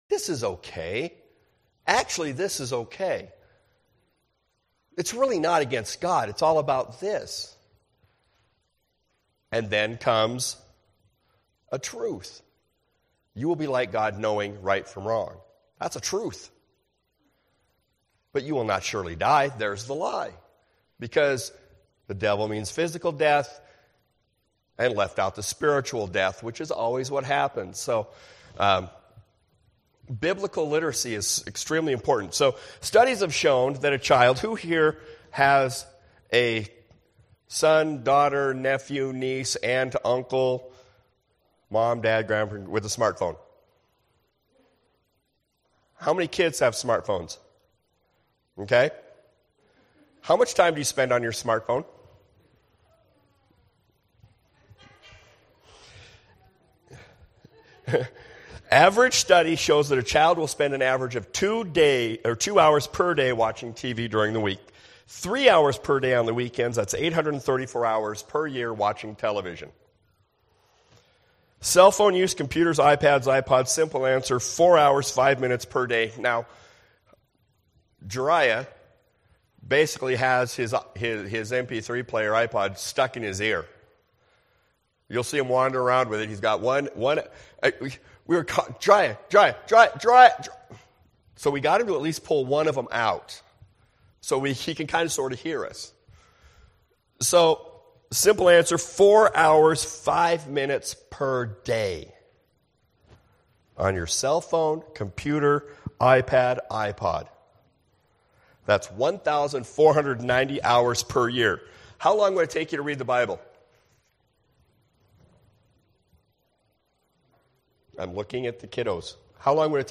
2018 Guest Speaker https